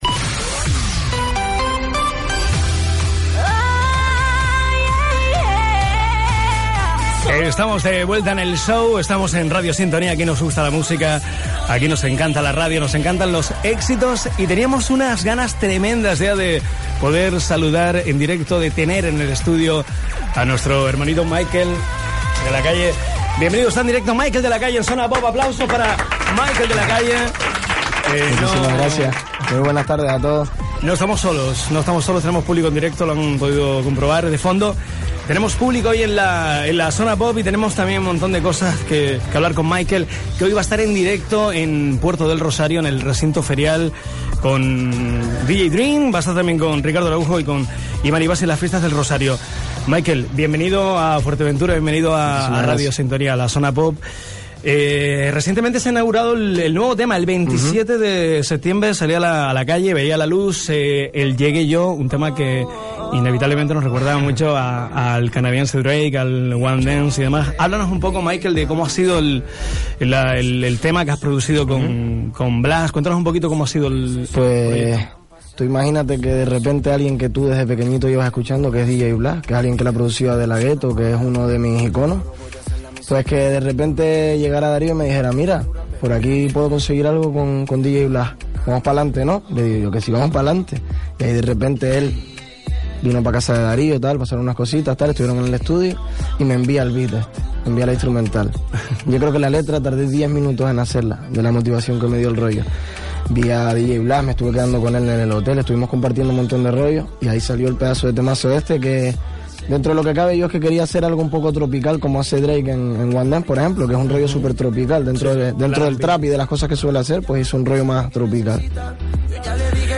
Zona POP Entrevista